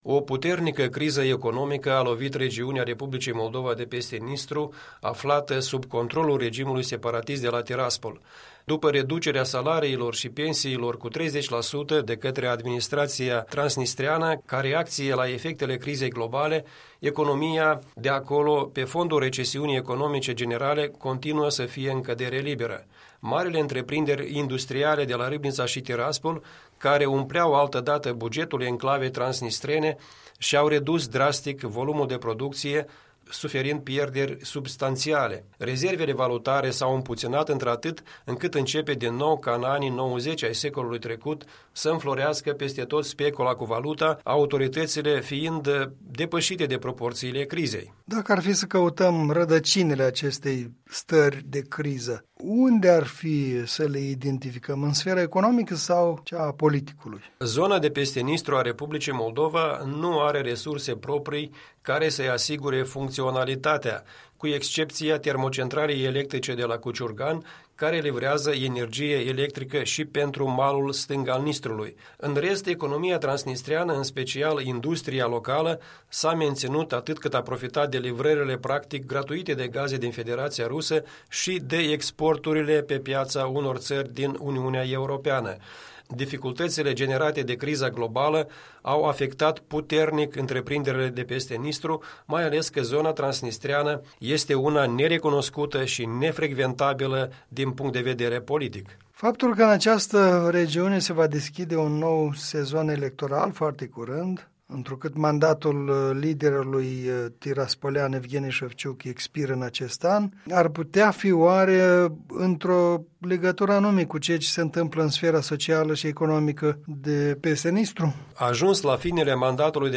Puncte de vedere în dialog